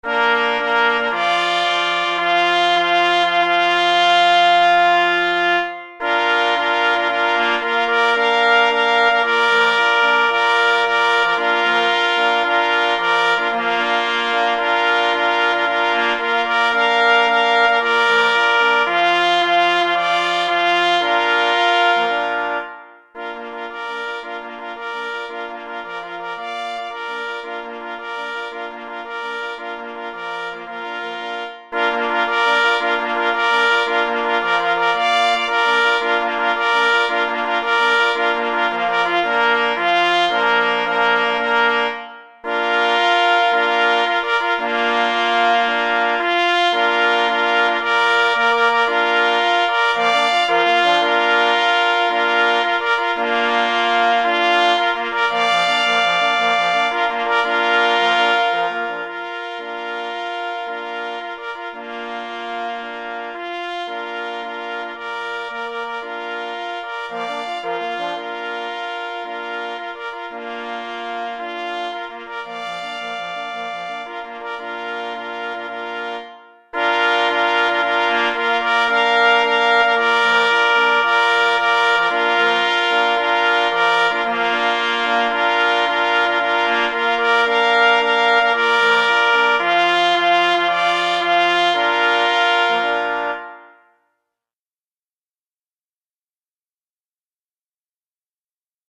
marsz koncertowy na 2 plesy i 2 parforsy
Radosny powrót z kniei. Marsz myśliwski Kompozytor